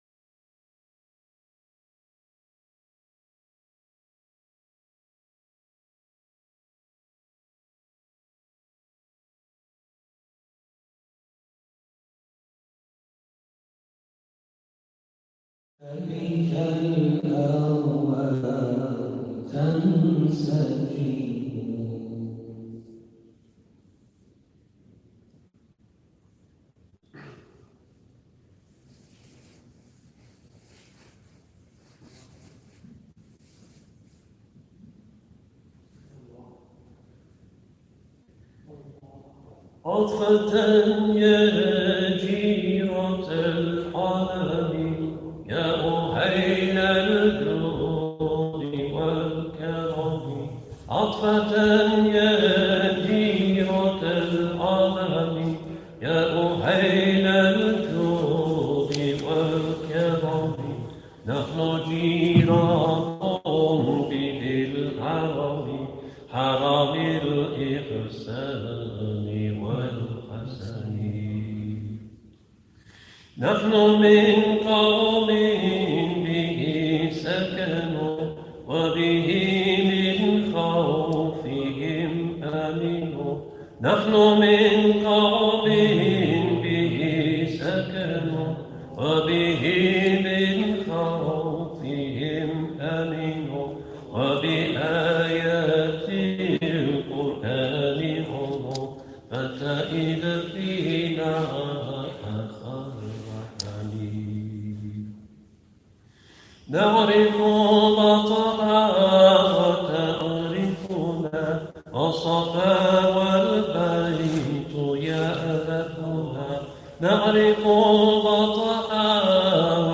Ashura from the Cambridge Central Mosque.mp3